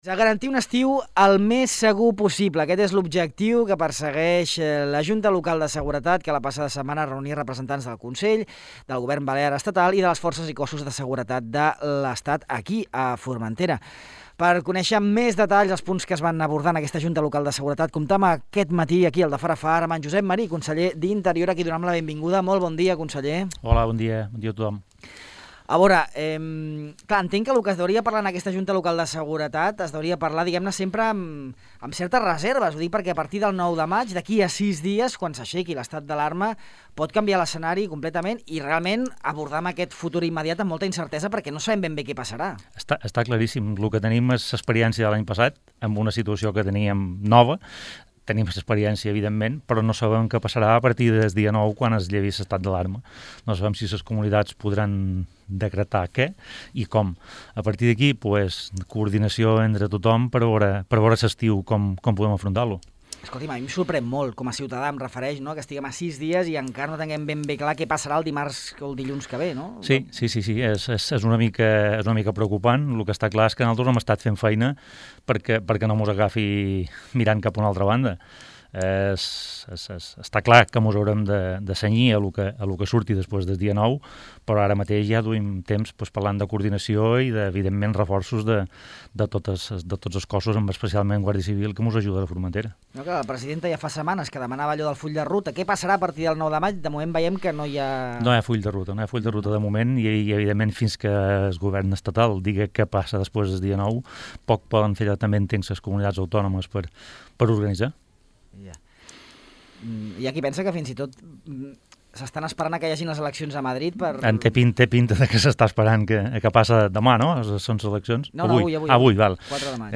El conseller d’Interior, Josep Marí, ha explicat a Ràdio Illa les principals demandes de Formentera en la Junta Local de Seguretat celebrada la passada setmana a l’illa, i que reuní a responsables del Govern insular, autonòmic i estatal, a més de representants dels cossos i forces de seguretat. Marí ha assenyalat que a més de l’habitual desplegament de la Guàrdia Civil de Trànsit i de les unitats especialitzades ROCA i USECI , el Consell ha sol·licitat un desplegament en l’àmbit marí.